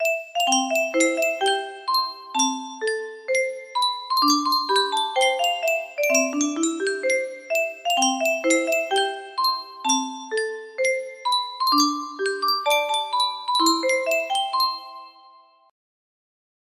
Yunsheng Music Box - Unknown Tune 1085 music box melody
Full range 60